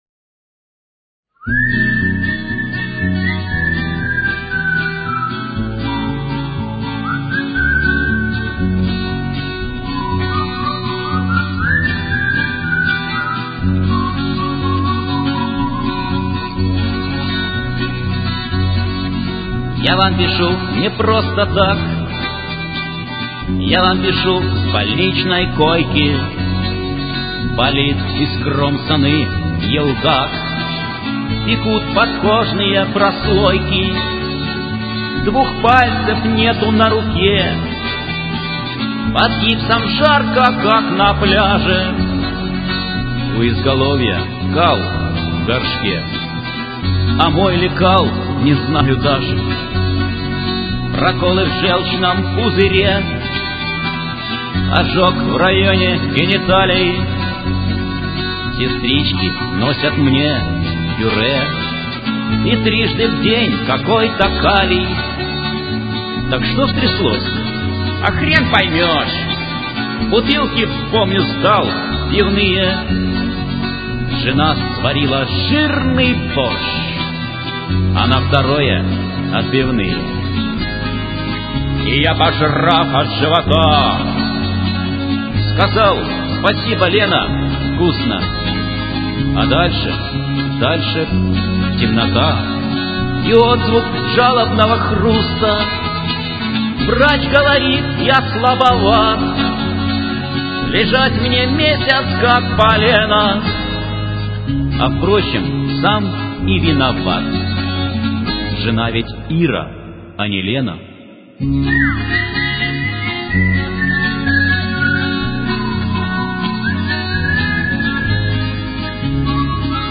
~ Песни под гитару ~